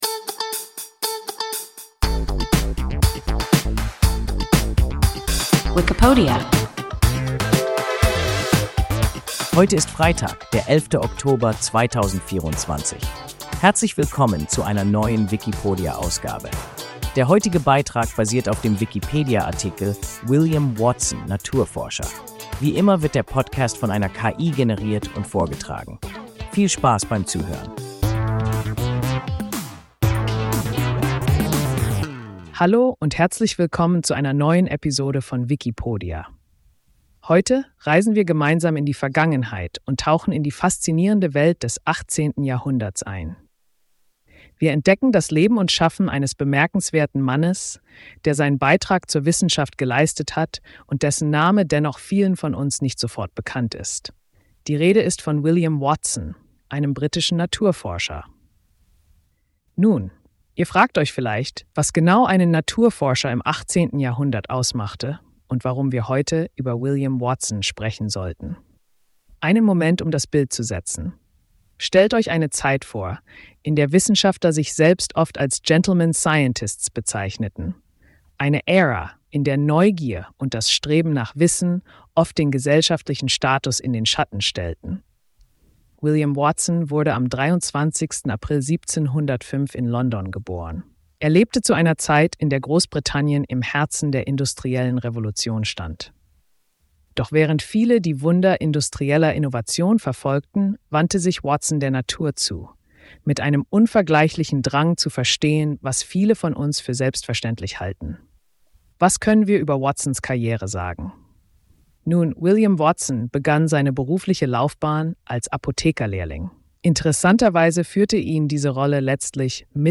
William Watson (Naturforscher) – WIKIPODIA – ein KI Podcast